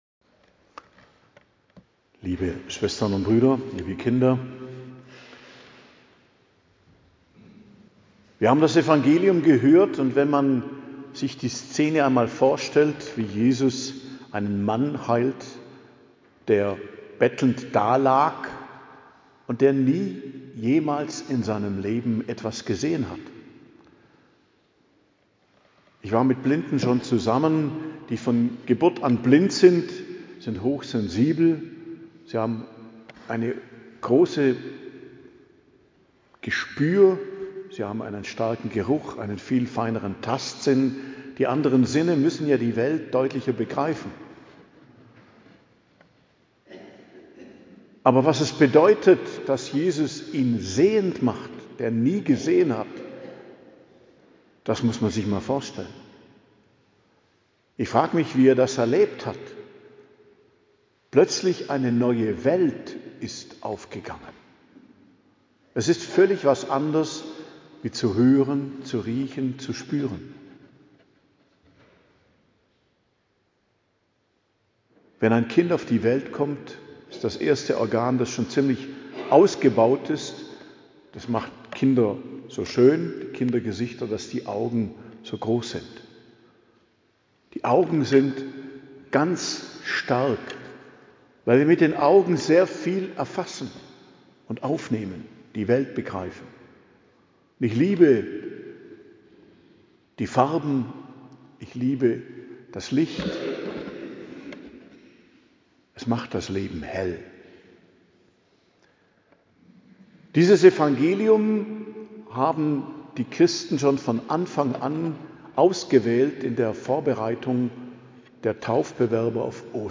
Predigt zum 4. Fastensonntag, 15.03.2026